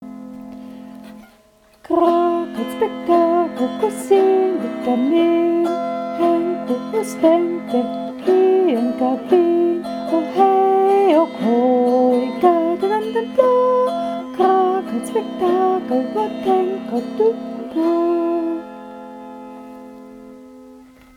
schwedischen Liedern